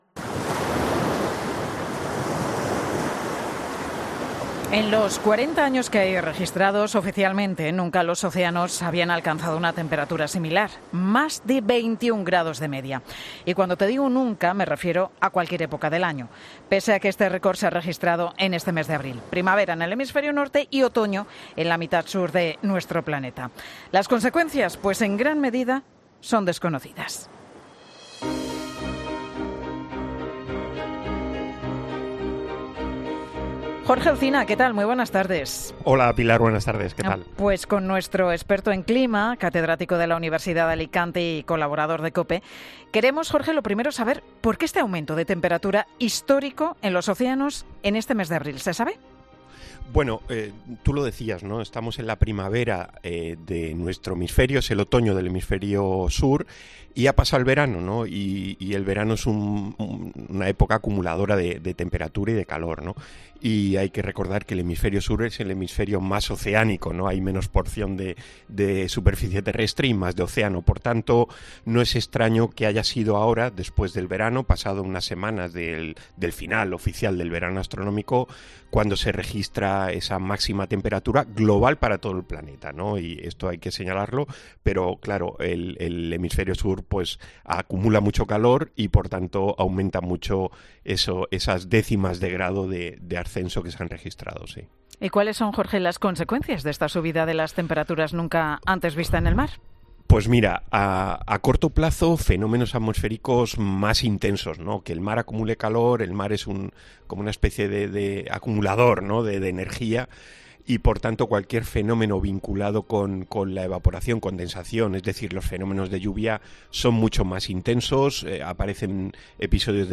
Tal y como ha comentado la presentadora y directora de 'Mediodía COPE' Pilar García Muñiz en antena, porque en el litoral mediterráneo ha habido una subida de temperaturas.